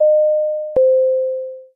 chime.mp3